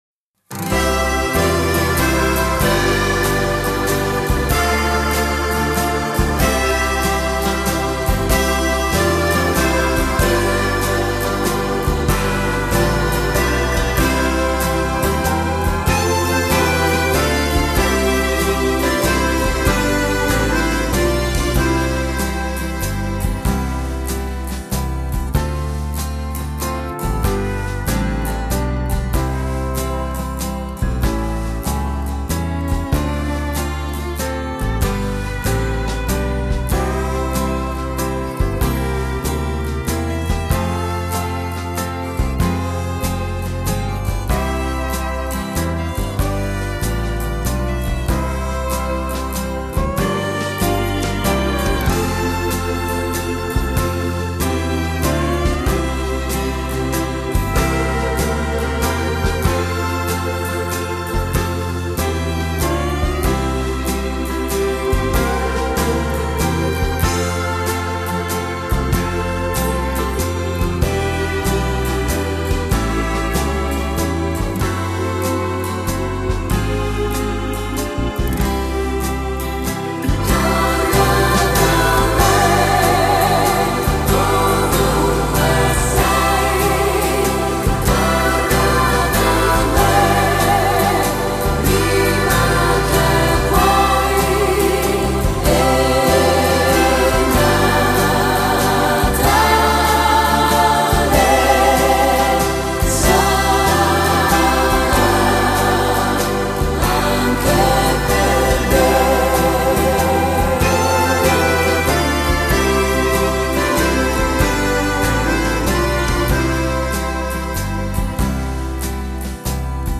Genere: Valzer lento
Scarica la Base Mp3 (3,68 MB)